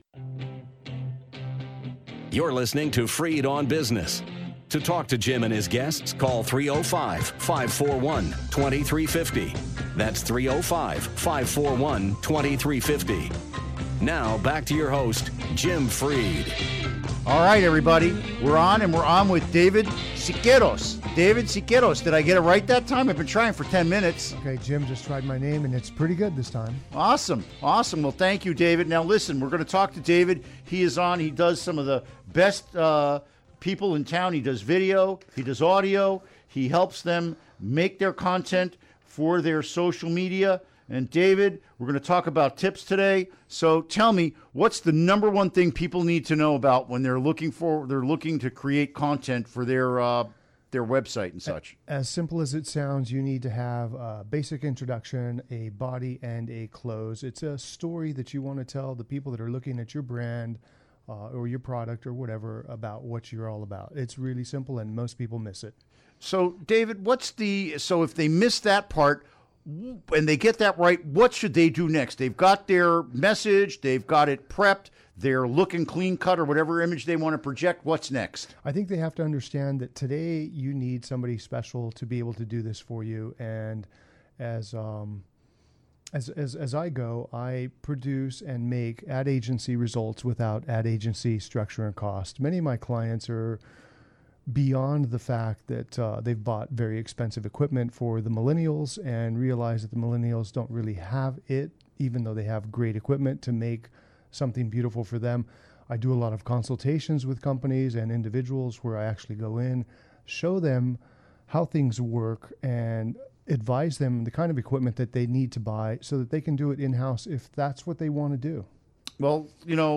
Interview Segment Download Now!